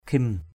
/kʱim/
khim.mp3